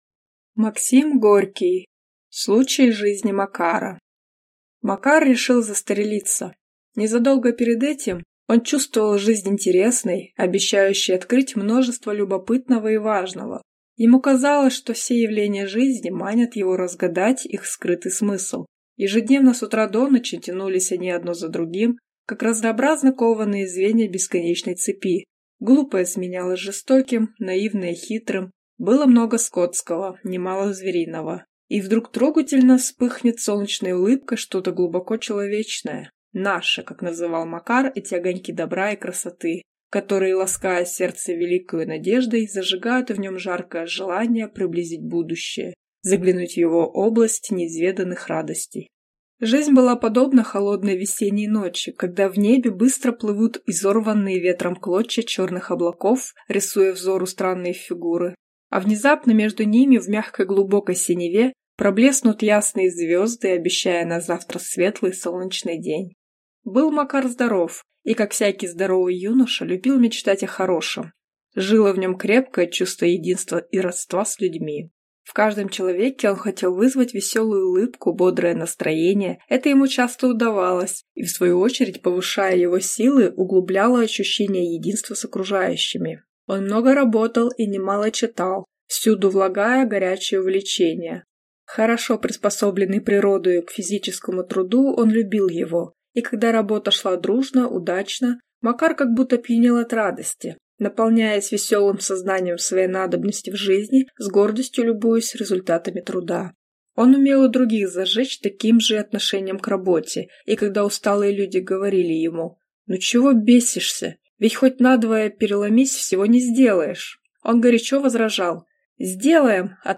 Аудиокнига Случай из жизни Макара | Библиотека аудиокниг